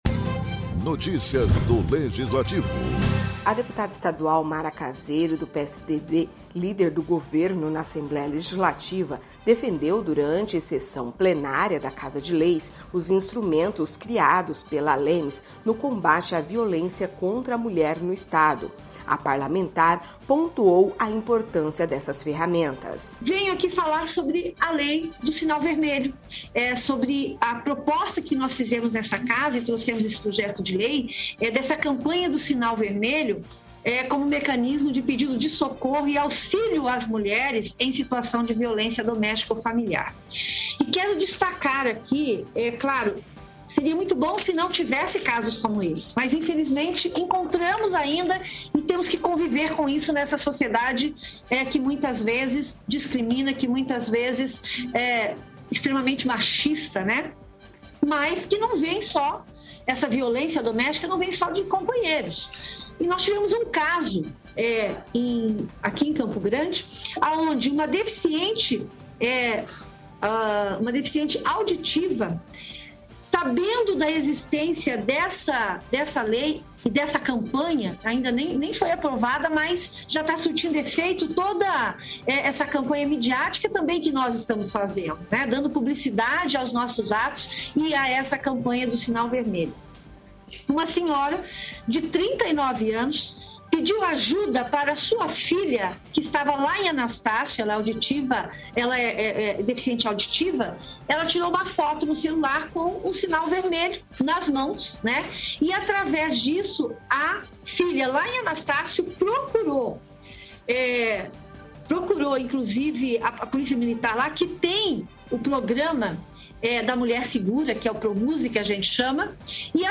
A deputada Mara Caseiro (PSDB), líder do Governo na Casa de Leis, defendeu durante sessão plenária da Assembleia Legislativa de Mato Grosso do Sul (ALEMS) os instrumentos criados pela Casa de Leis no combate à violência contra a mulher no Estado.